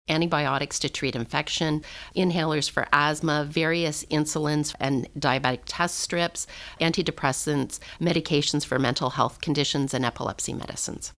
Recording Location: toronto
Type: News Reports